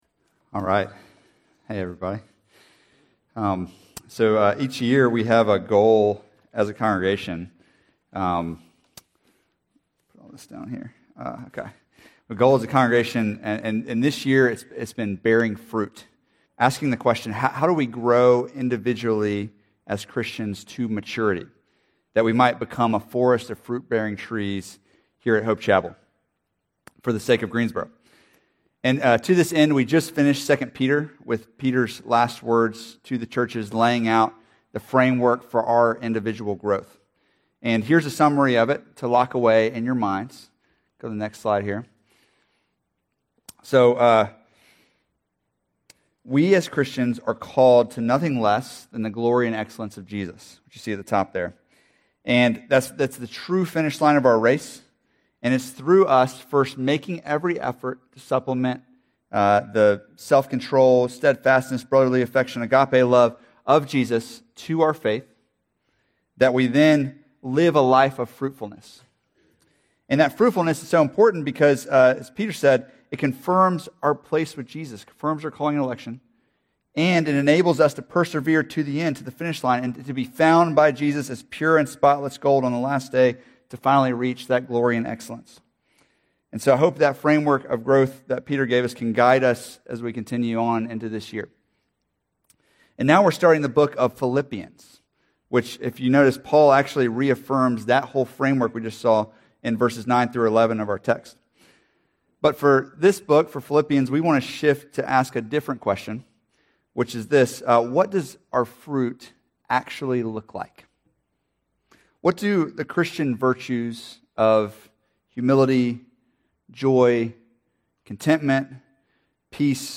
Sermons – Hope Chapel